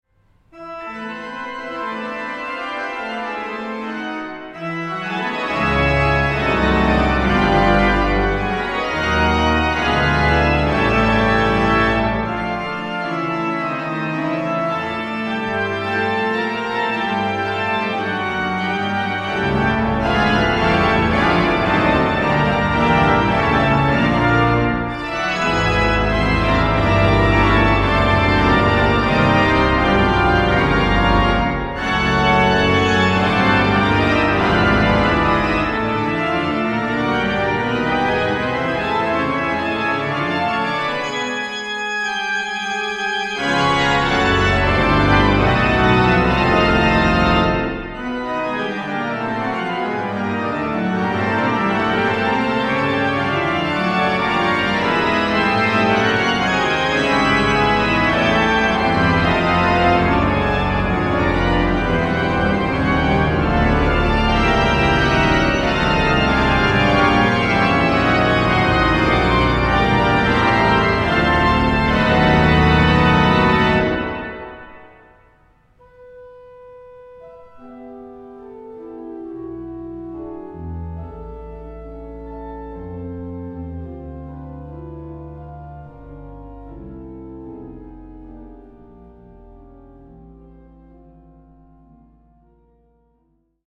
Konzert CD
WALCKER-Orgel von 1928.